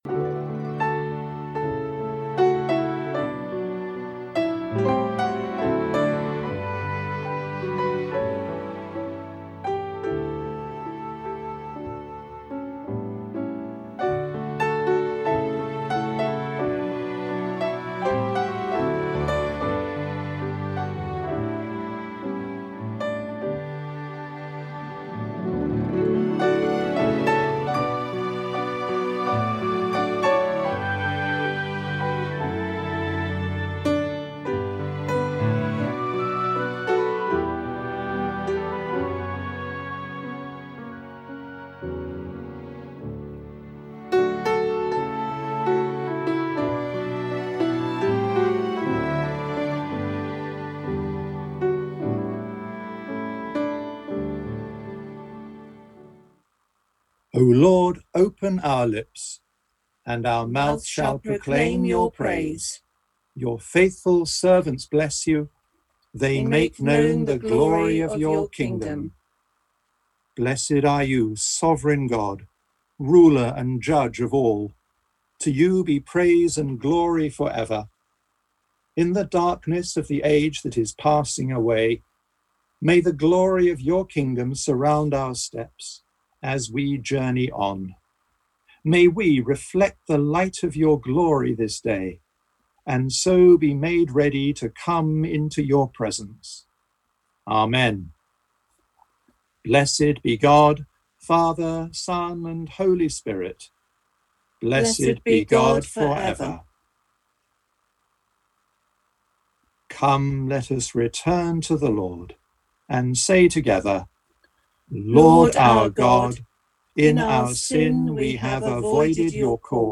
Morning Prayer - 15 Nov 2020
Audio from the Zoom and Conference Call on 15th November 2020